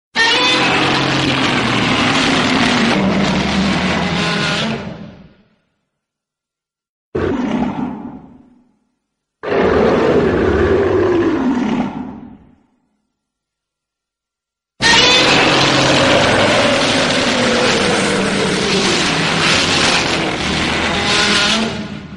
Godzilla Roar Sound Button - Free Download & Play
1. Play instantly: Click the sound button above to play the Godzilla Roar sound immediately in your browser.